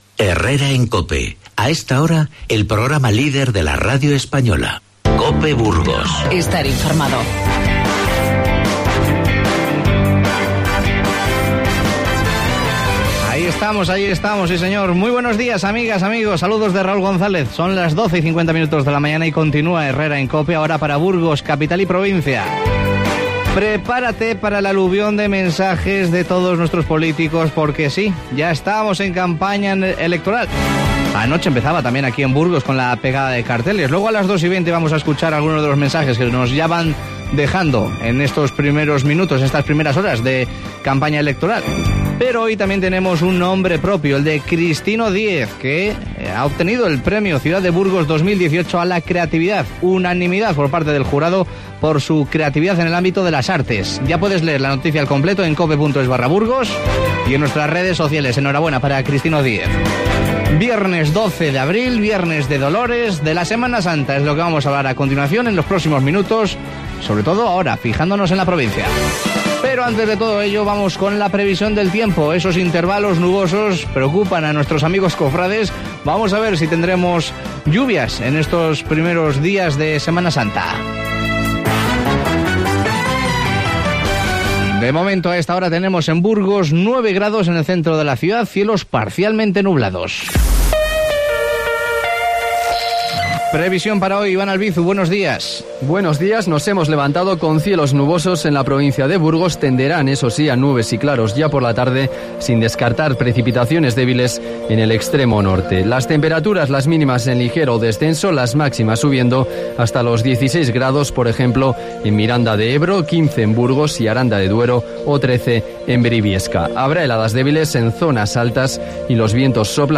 Nos acercamos hasta Aranda de Duero para conocer cómo es la Semana Santa de esta localidad y qué nos proponen para los próximos días. Nos lo cuenta su alcaldesa, Raquel González.